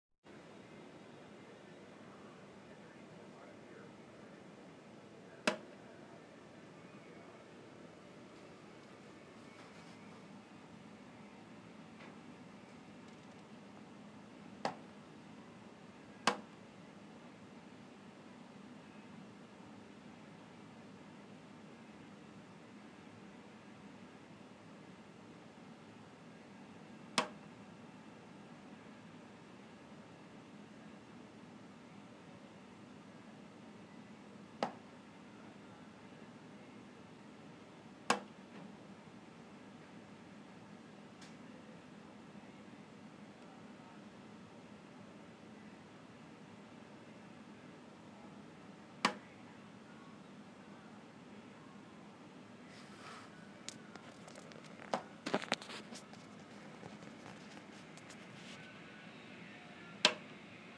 Slow Roof Leak
slow-roof-leak.m4a